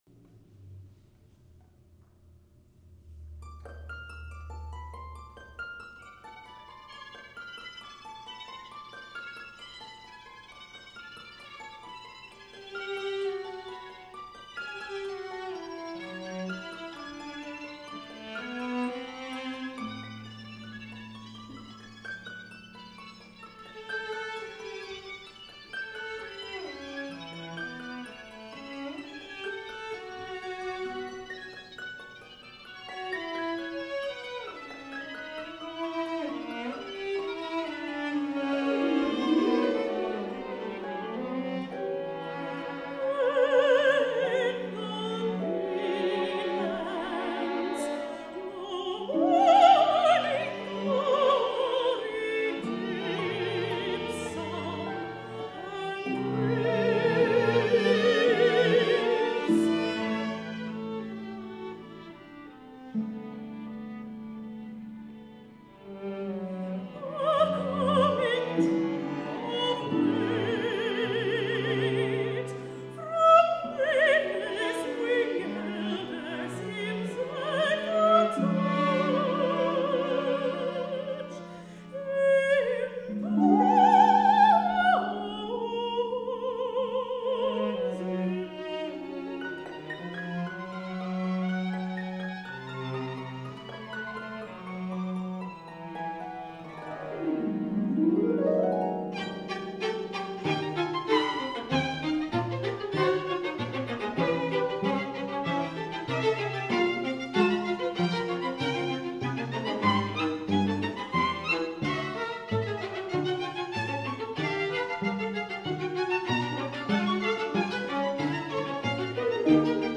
The beauties of nature in chamber music form.
mezzo-soprano
violin
viola
cello
harp